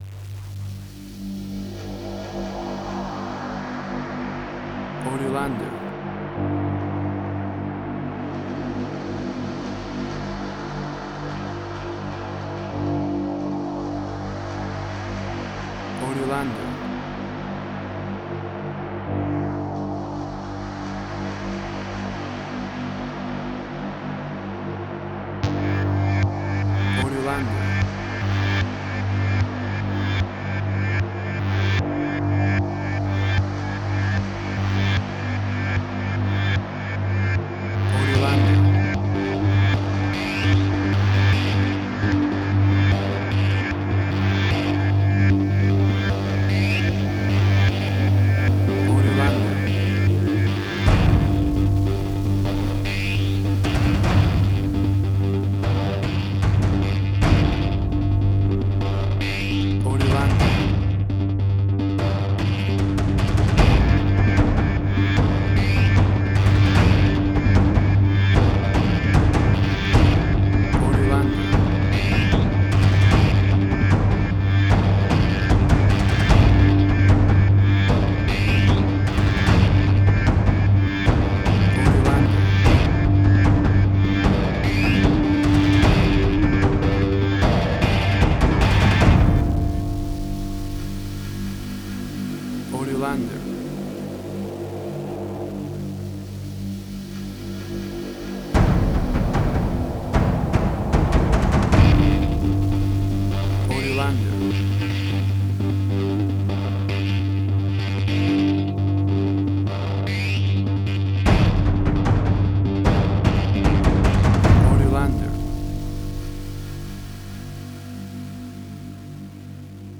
Suspense, Drama, Quirky, Emotional.
Tempo (BPM): 151